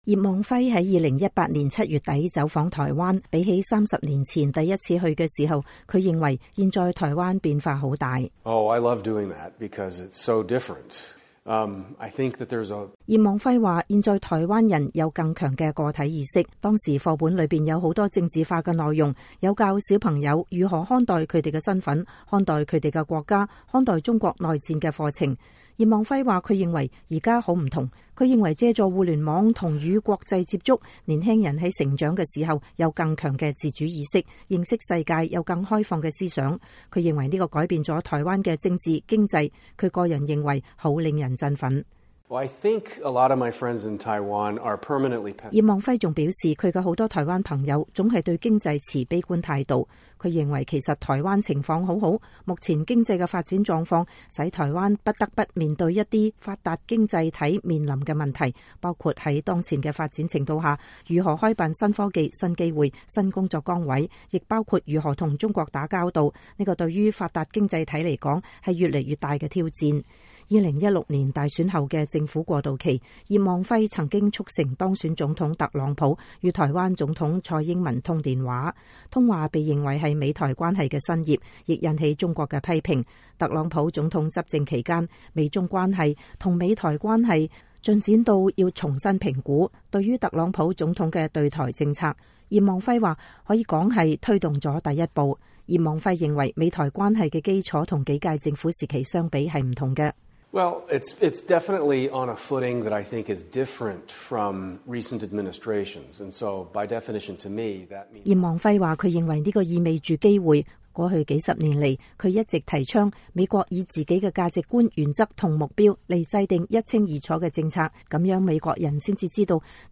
美國之音專訪葉望輝 談美台關係
前美國副總統切尼國安顧問葉望輝。